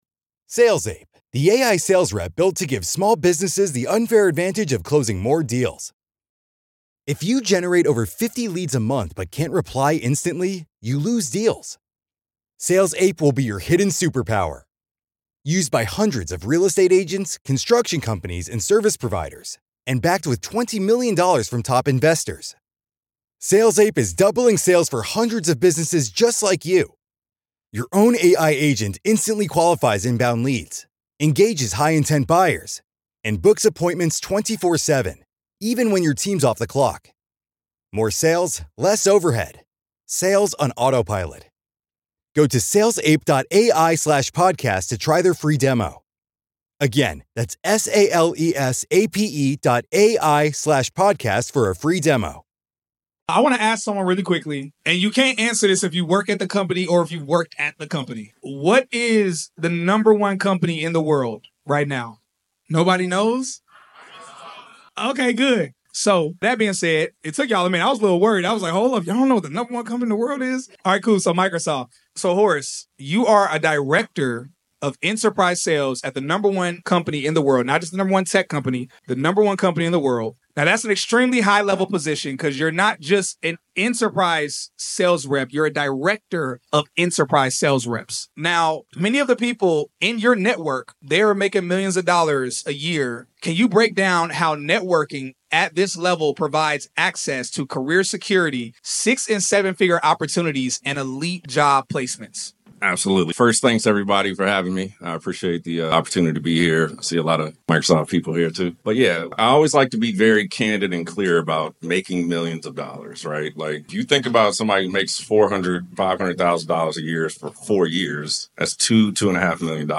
This was from our last tech event "Grown n Techie"For everyone who missed the last event because it sold out before you could get your tickets, click here to get access to the ne…